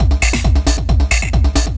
DS 135-BPM D2.wav